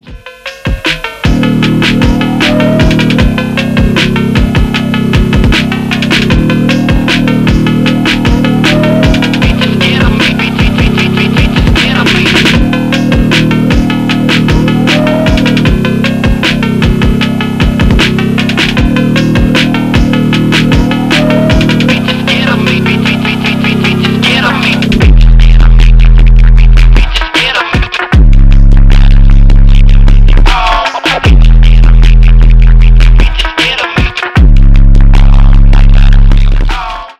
• Качество: 320 kbps, Stereo
Рэп и Хип Хоп
громкие